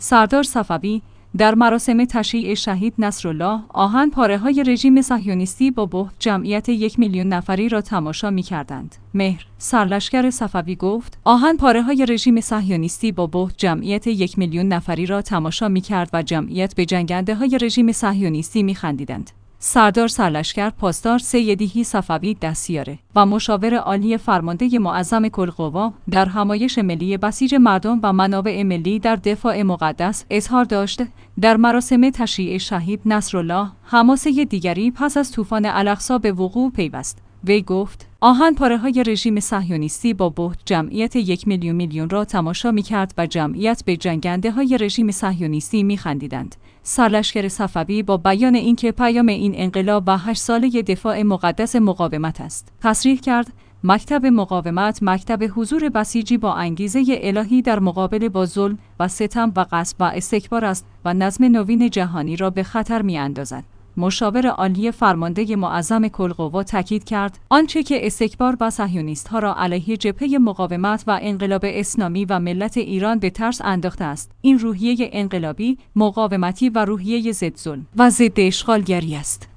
سردار سرلشکر پاسدار «سیدیحیی صفوی» دستیار و مشاور عالی فرمانده معظم کل قوا، در همایش ملی بسیج مردم و منابع ملی در دفاع مقدس، اظهار داشت: در مراسم تشییع شهید